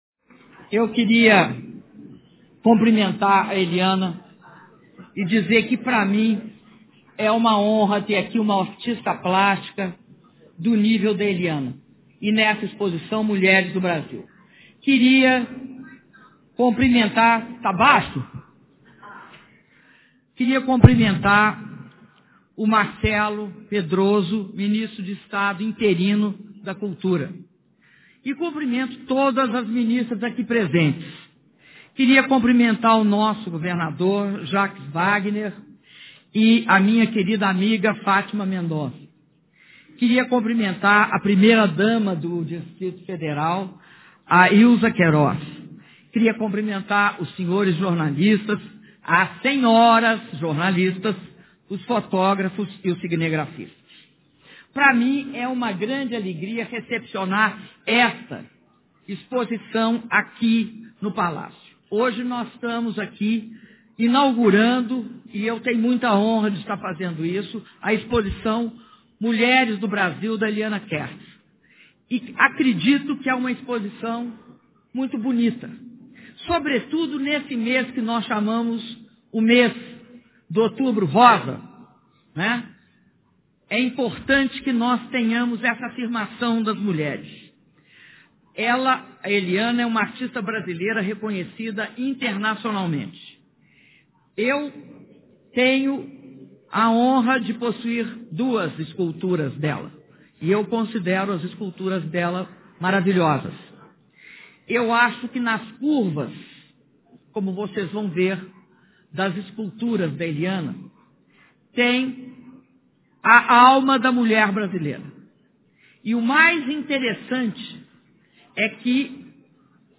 Áudio do discurso da Presidenta durante abertura da exposição "Mulheres do Brasil" - Brasília/DF (04min23s)